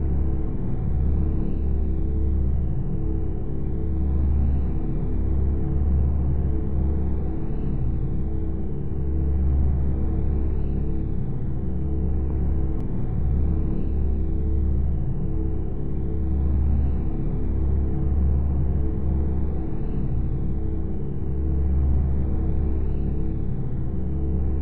ships / movement / quantimeengine.ogg
quantimeengine.ogg